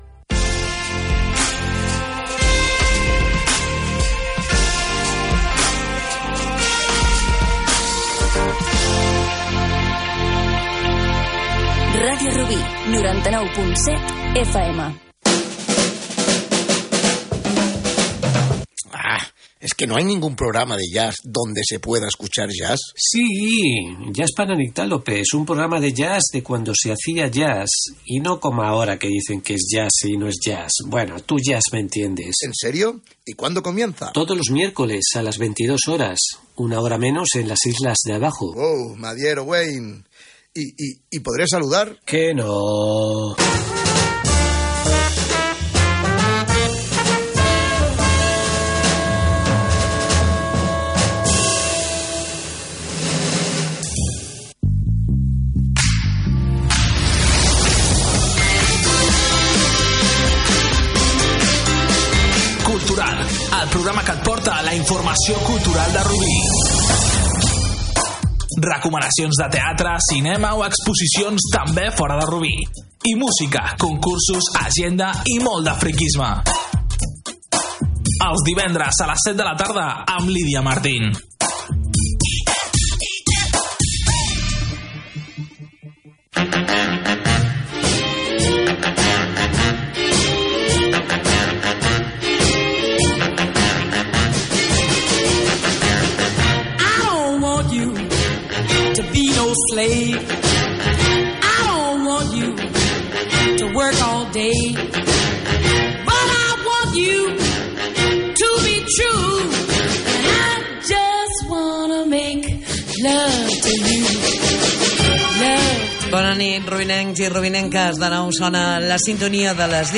Indicatiu de l'emissora, promoció dels programes "Jazz para nictálopes" i "Cultural", sintonia, presentació, equip i entrevista a